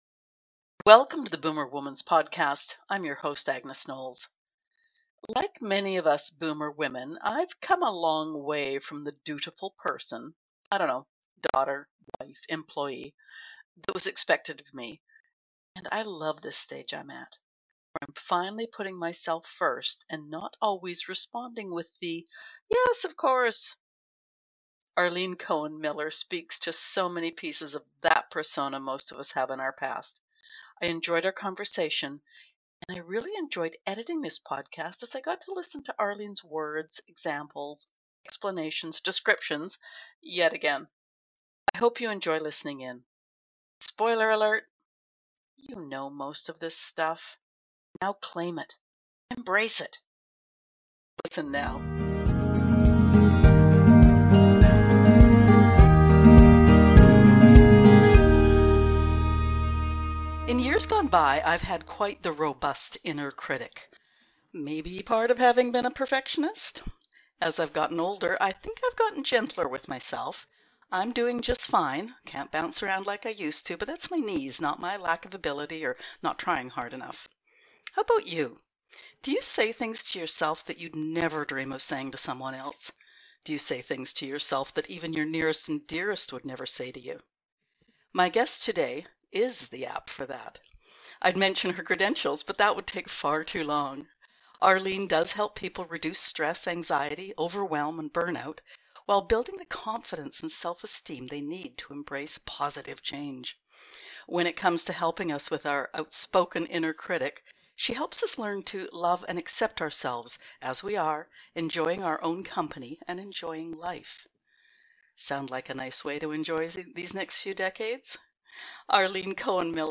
in conversation with